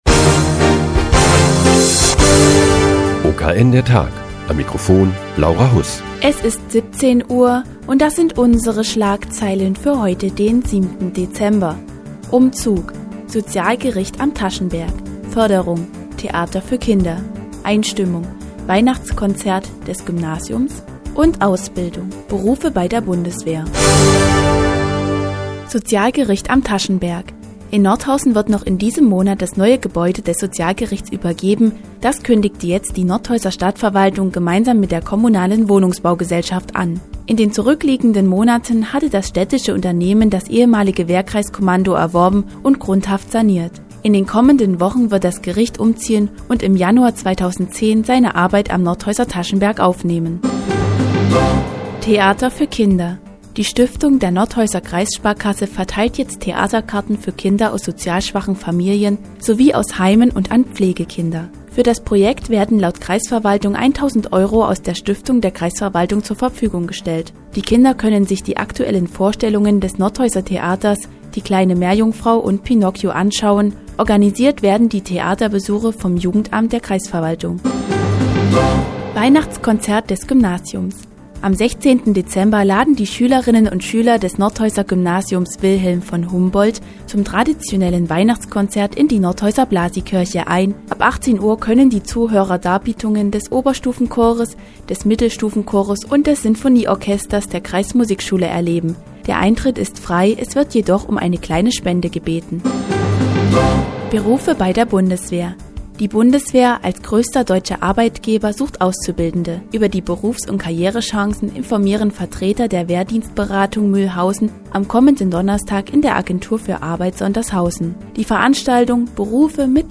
Die tägliche Nachrichtensendung des OKN ist nun auch in der nnz zu hören. Heute geht es um das neue Gebäude des Sozialgerichts, das Weihnachtskonzert des Humboldt-Gymnasiums und Berufe bei der Bundeswehr.